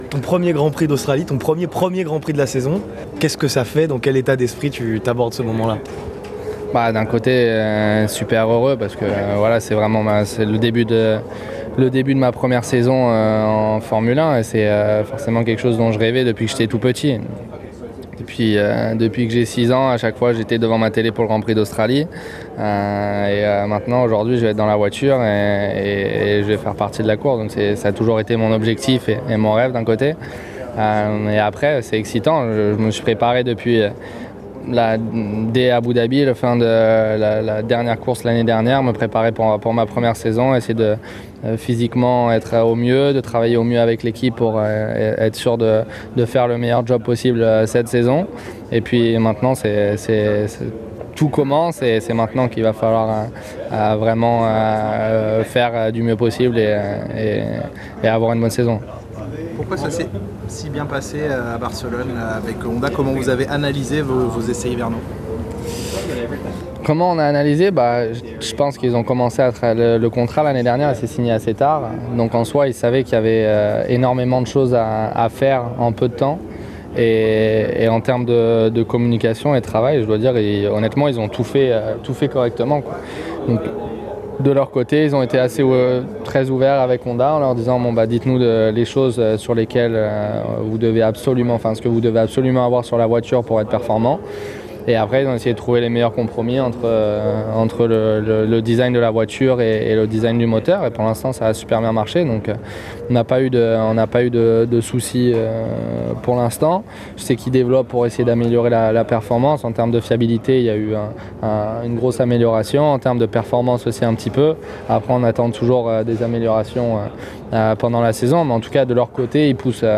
Rencontre avec les médias pour Pierre Gasly, l'un des espoirs français de la Formule 1 pour la saison 2018.. Il se livre avant le départ de sa première saison complète à Melbourne.